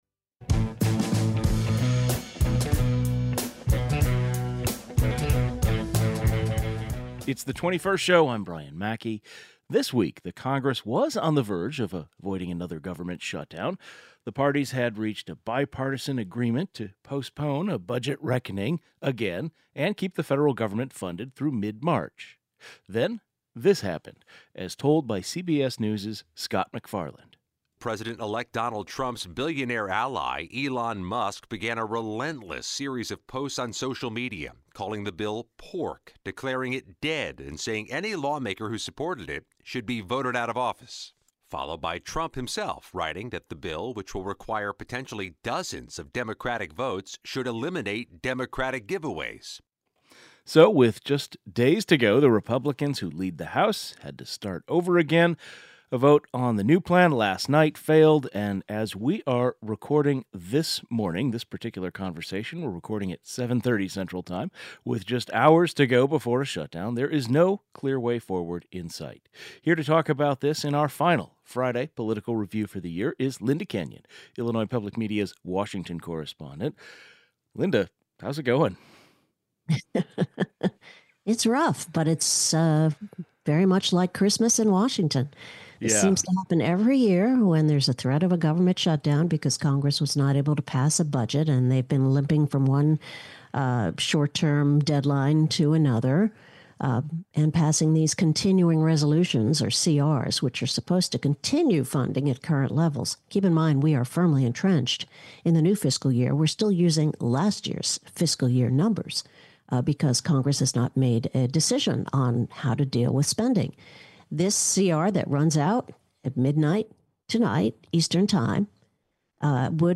Guest